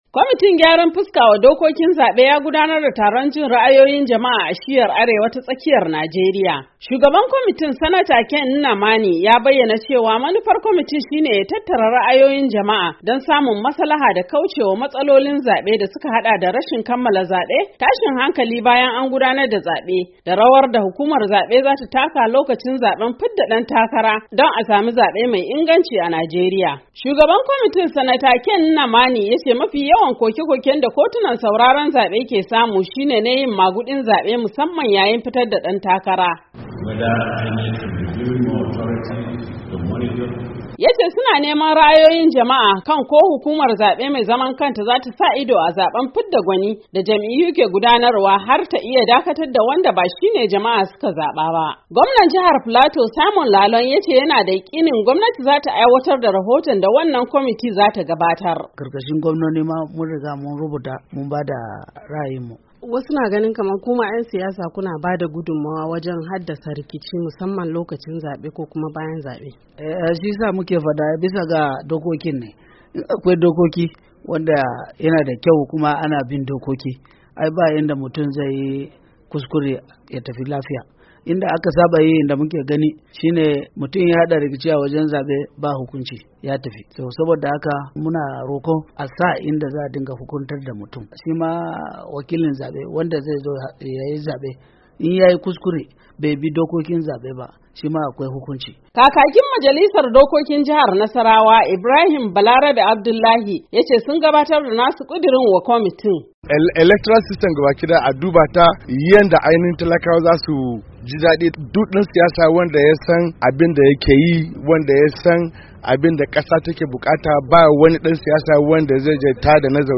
Kwamitin gyara dokokin zaben Najeriya da Shugaba Muhammad Buhari ya kafa a karkashin jagorancin tsohon kakakin majalisar dattawa Sanata Ken Nnemani, ya gudanar da taron jin ra'ayoyin jama'ar jihohin arewa ta tsakiya a Jos, babban birnin Filato.
Taron na Jos shi ne na jin ra'ayoyin al'ummar jihohin arewa ta tsakiya.